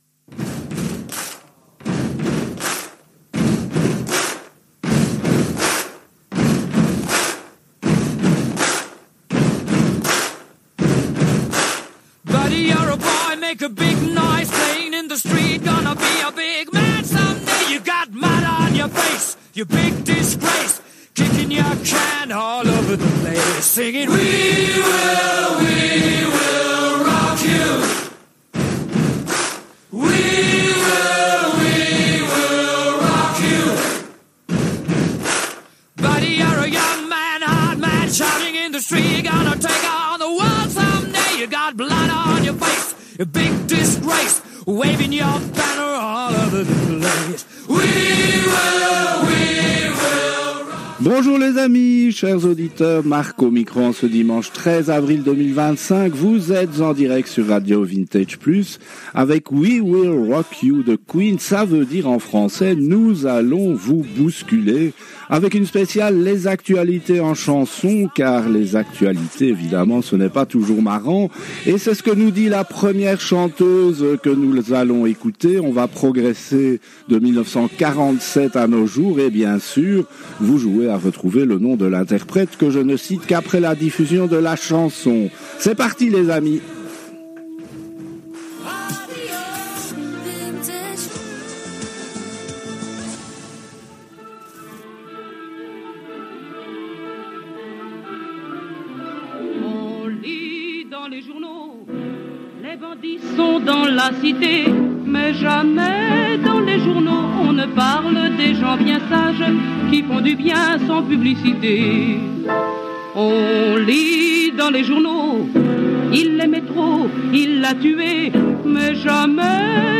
C’est une nouvelle émission spéciale à thème que RADIO VINTAGE PLUS a diffusée le dimanche 13 avril 2025 à 10h en direct des studios de RADIO RV+ en BELGIQUE